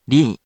If you press the 「▶」button on the virtual sound player, QUIZBO™ will read the random hiragana to you.
In romaji, 「り」 is transliterated as 「ri」which sounds sort of like 「lee」